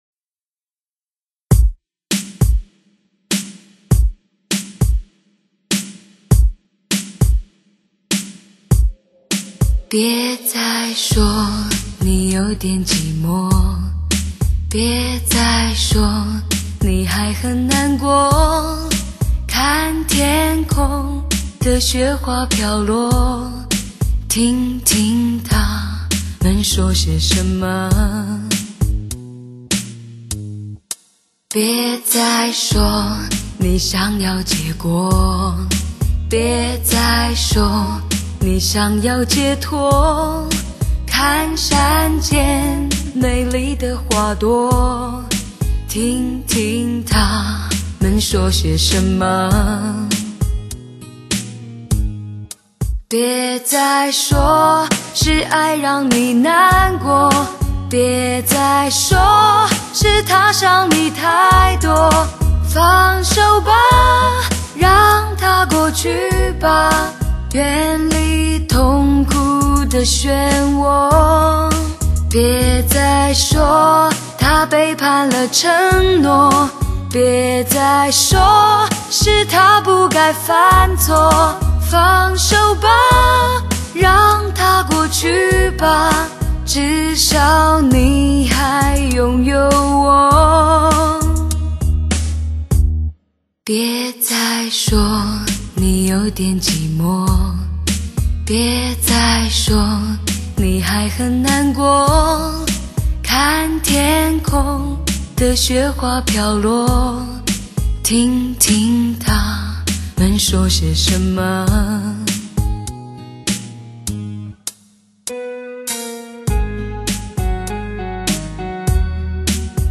强势高清解码还原真声 带来超乎想象的震撼级高临场感